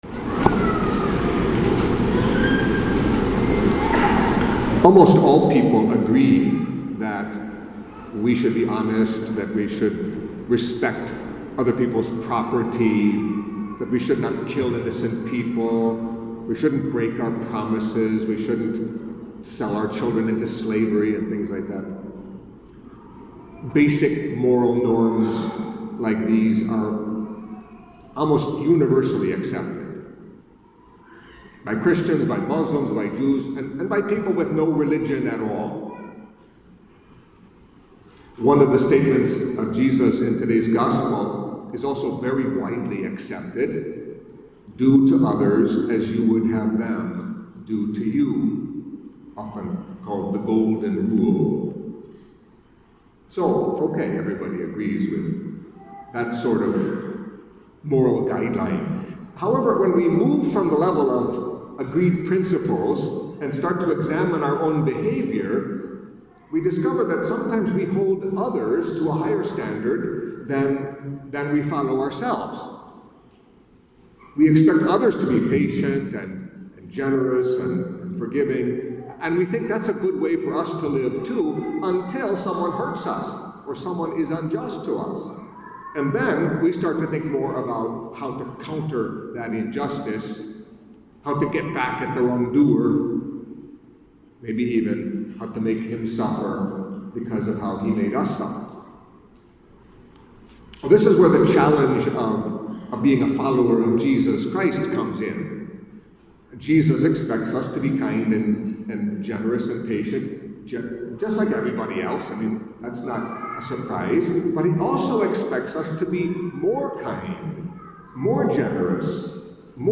Catholic Mass homily for Seventh Sunday in Ordinary Time